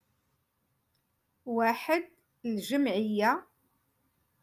Moroccan Dialect- Rotation Three- Lesson Eight